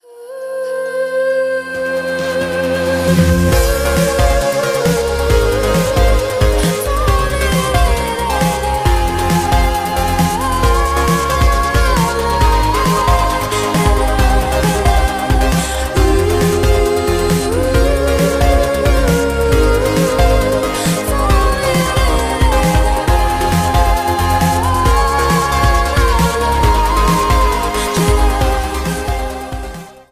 Поп Музыка
тихие